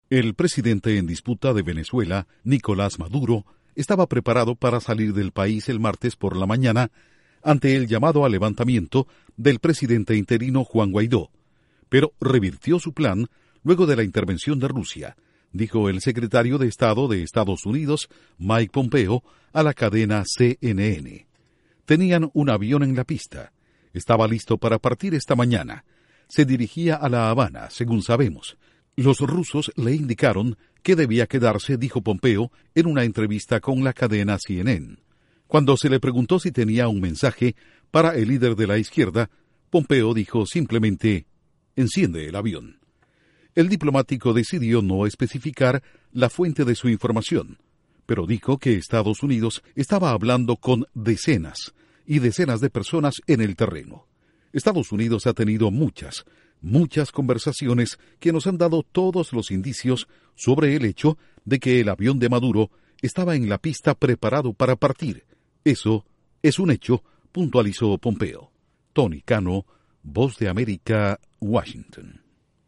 Maduro iba a irse de Venezuela, pero Rusia lo convenció de quedarse asegura Secretario Estado EE.UU. … Informa desde la Voz de América en Washington